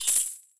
money.wav